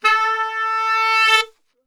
A 2 SAXSWL.wav